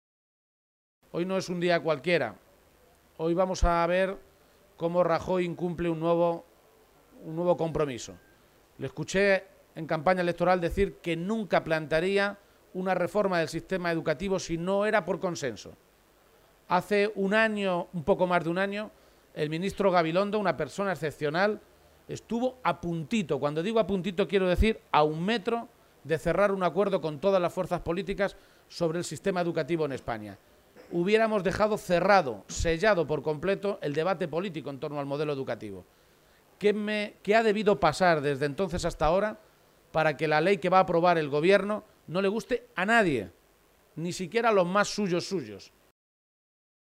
El secretario general del PSOE de Castilla-La Mancha, Emiliano García-Page, ha participado hoy en la tradicional Feria de San Isidro de Talavera de la Reina, y ha aprovechado su visita a la Ciudad de la Cerámica para compartir un encuentro con los medios de comunicación en el que ha repasado las cuestiones de actualidad regional.
Cortes de audio de la rueda de prensa